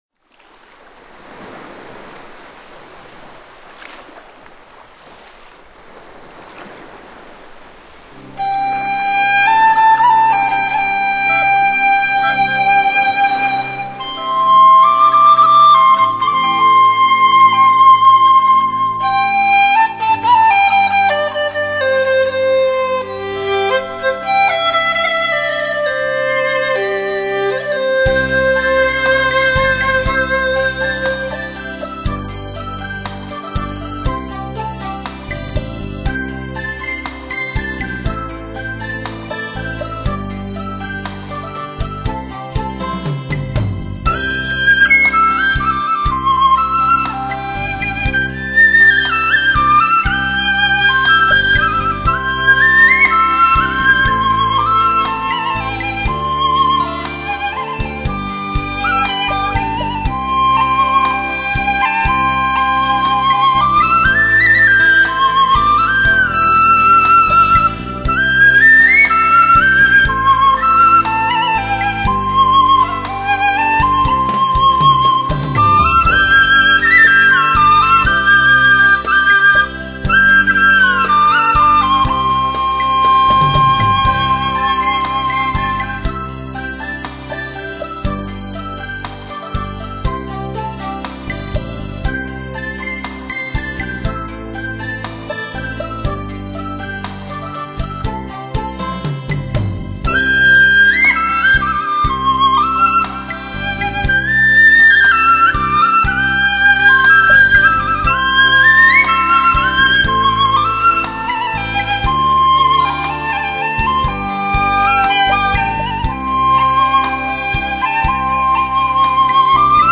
采用世界最新的美国DTS-ES顶级编码器，带来超乎想像震撼性的6.1环绕新体验。